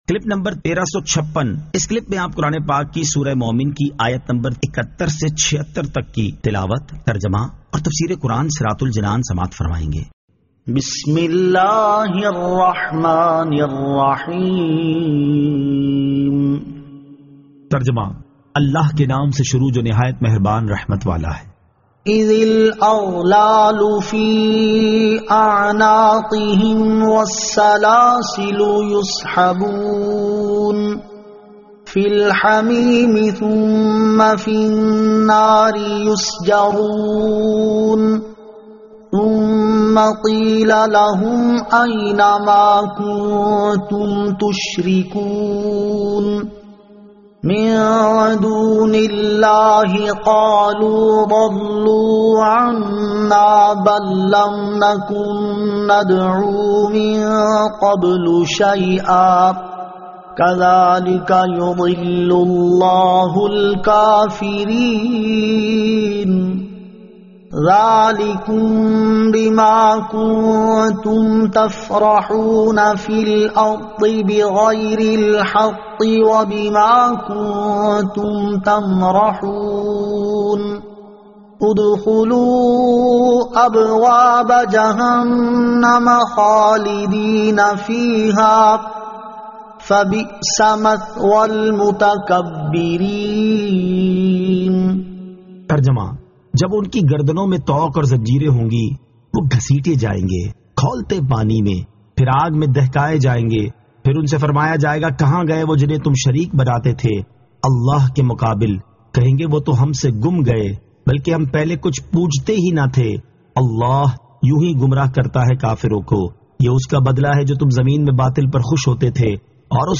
Surah Al-Mu'min 71 To 76 Tilawat , Tarjama , Tafseer